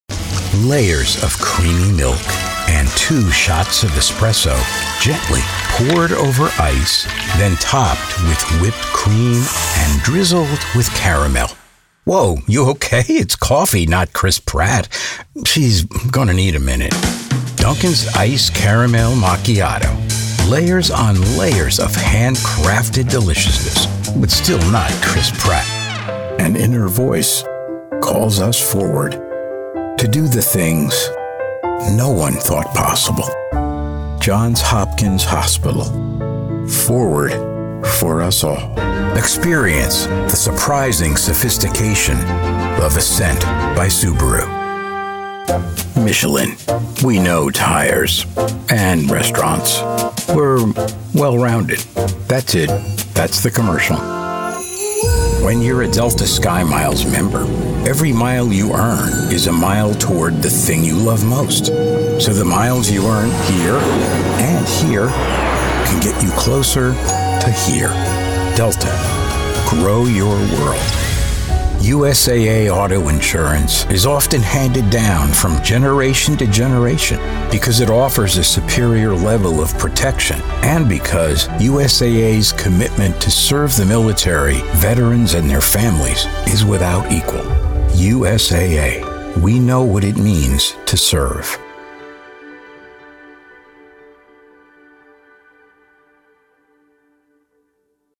Commercial Demo
Middle Aged
Senior